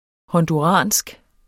honduransk adjektiv Bøjning -, -e Udtale [ hʌnduˈʁɑˀnsg ] Betydninger fra Honduras; vedr.